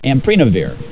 Pronunciation
(am PRE na veer)